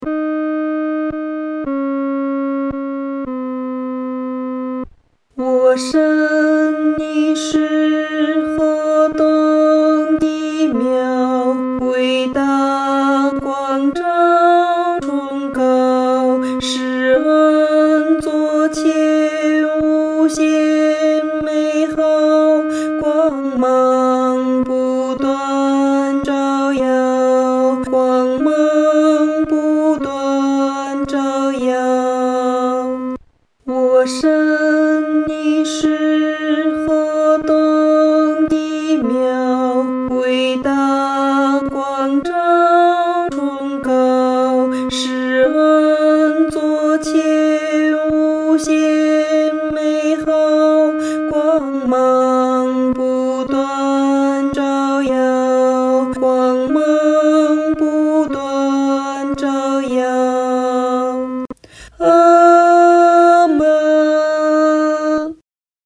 合唱
女低